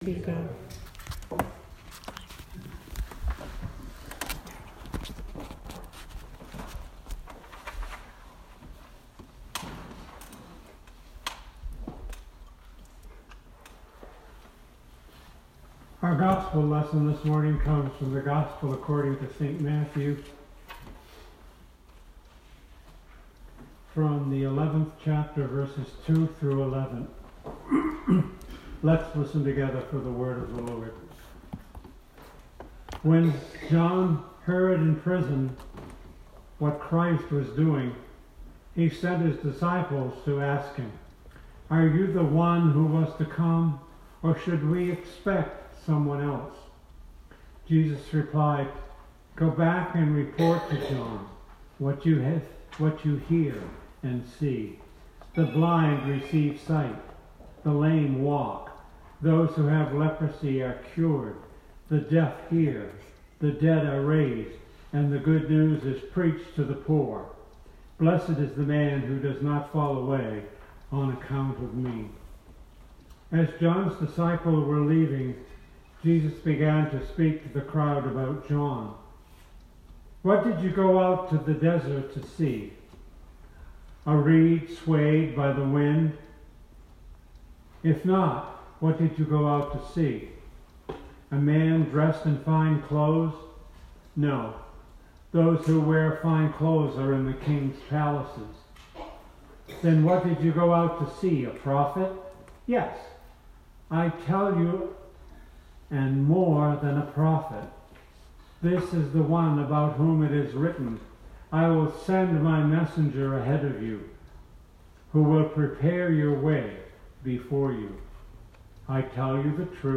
Sermon 2019-12-15